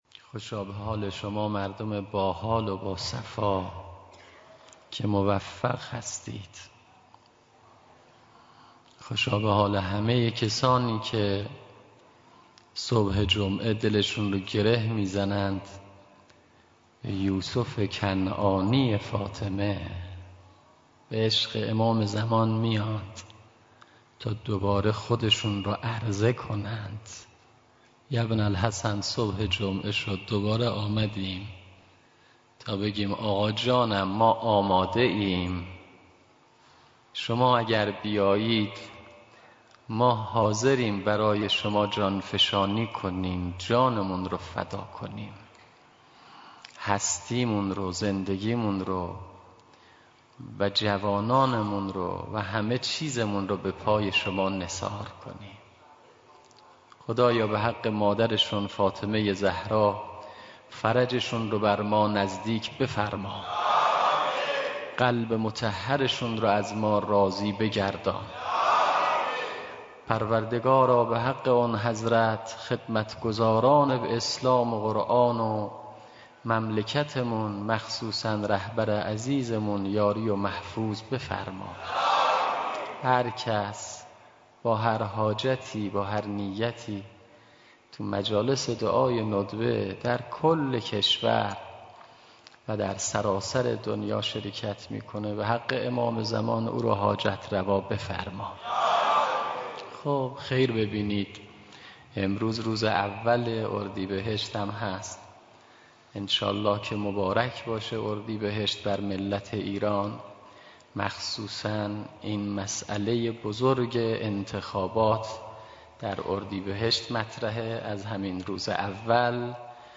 سخنرانی
دعای ندبه پخش زنده
مهدیه تهران